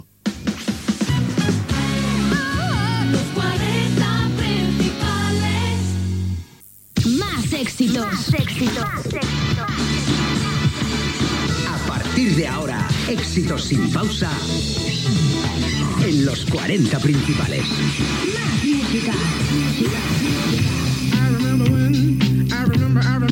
Jingle
Gènere radiofònic Musical